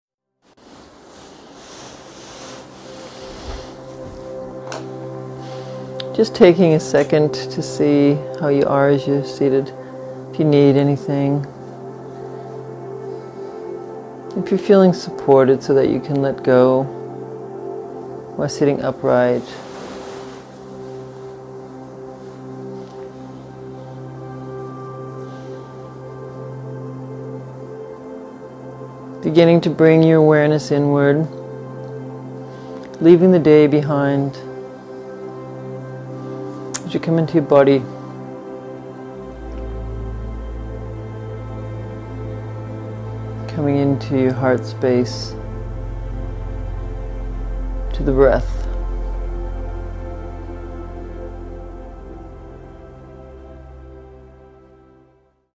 5 Questions & Alchemist Review Meditation